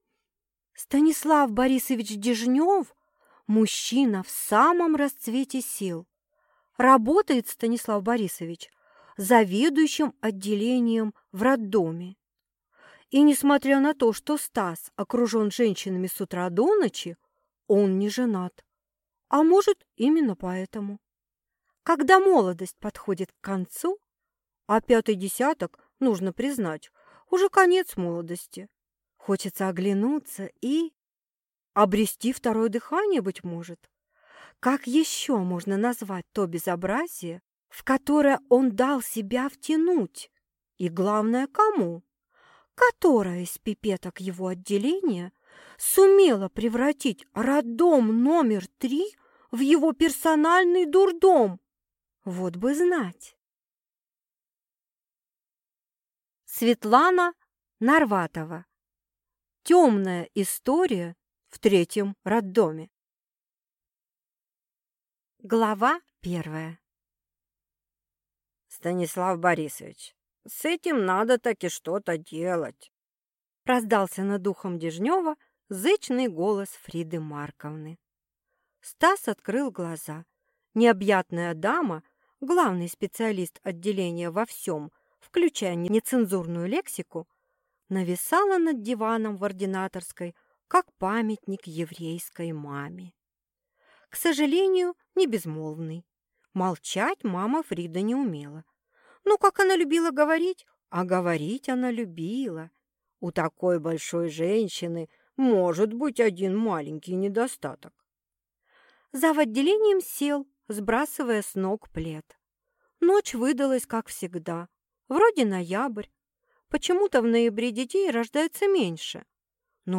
Аудиокнига Темная история в третьем роддоме | Библиотека аудиокниг